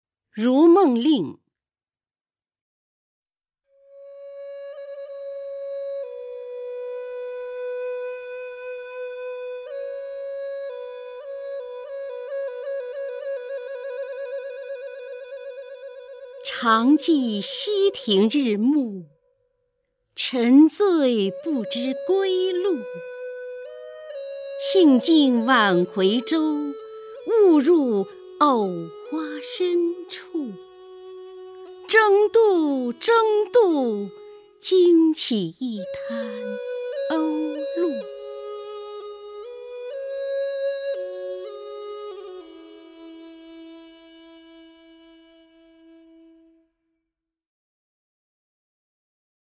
首页 视听 名家朗诵欣赏 姚锡娟
姚锡娟朗诵：《如梦令·常记溪亭日暮》(（南宋）李清照)　/ （南宋）李清照